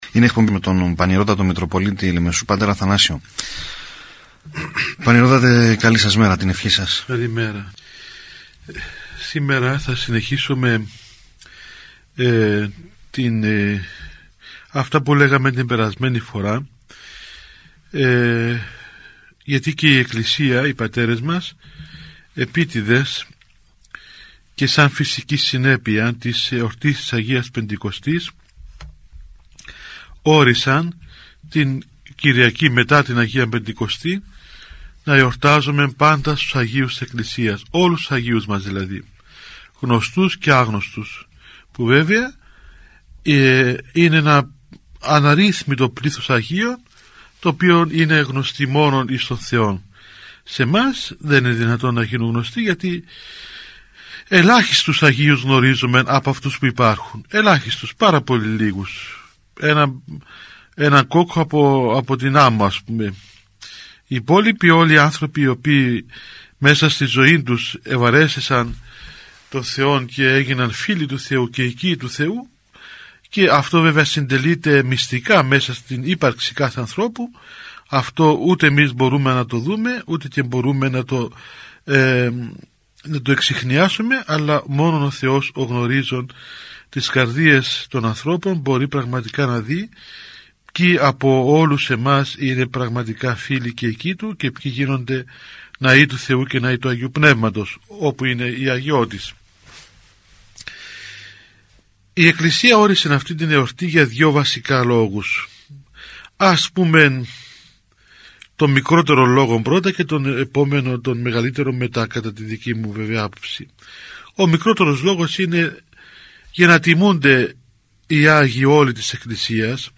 Ακολούθως σας παραθέτουμε ηχογραφημένη ομιλία – ραδιοφωνική εκπομπή του Πανιερωτάτου Μητροπ. Λεμεσού, π. Αθανασίου, η οποία αναφαίρεται στην εορτή – Κυριακή των Αγίων Πάντων.